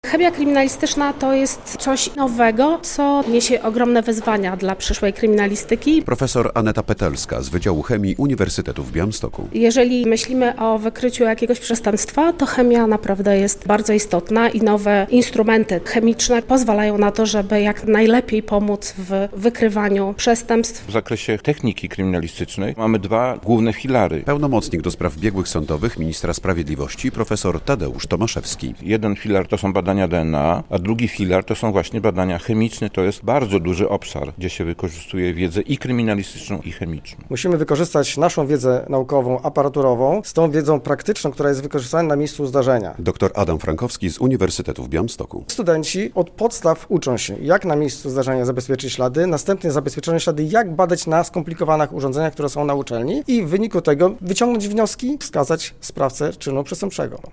Przestępca zostawia ślad, a jak go wykryć rozmawiają uczestnicy konferencji chemii kryminalistycznej
Konferencja CrimeChem 2025 - relacja